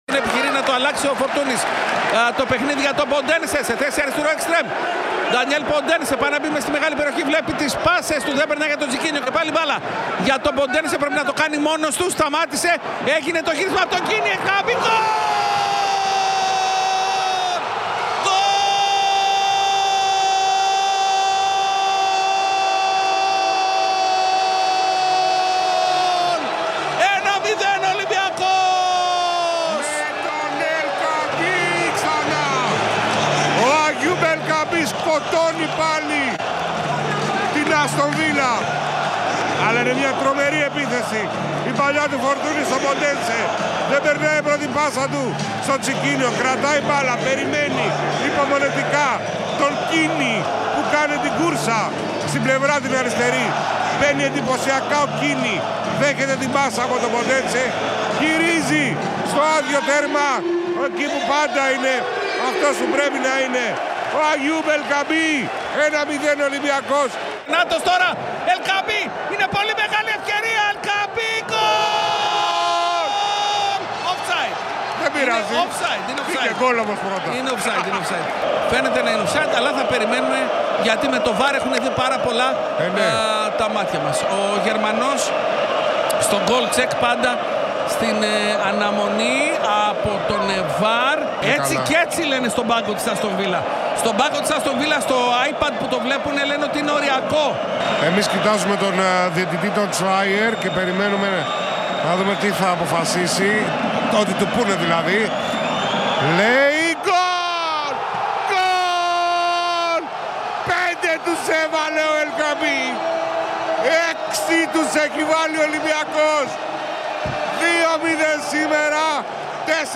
Ολυμπιακός - Άστον Βίλα: Έτσι περιέγραψε ο Real FM την σπουδαία πρόκριση στον τελικό του Europa Conference League